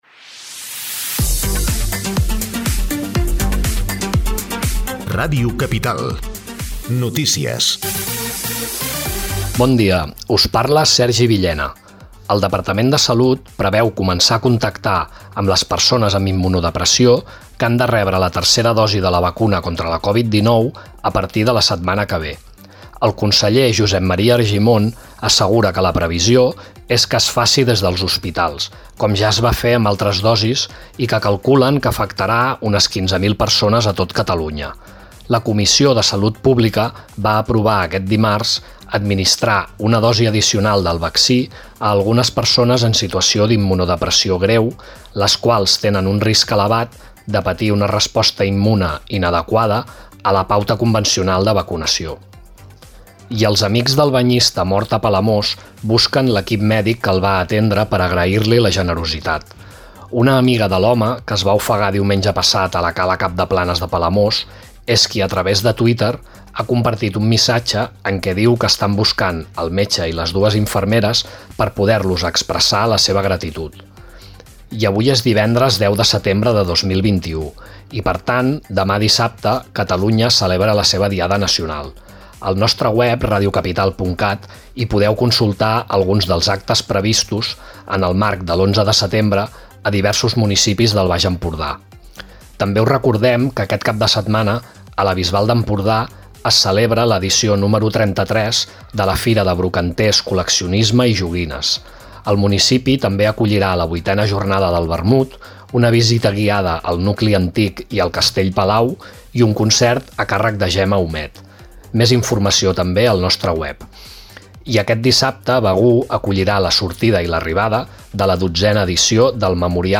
Butlletí informatiu - 10 setembre 2021 • Ràdio Capital de l'Empordà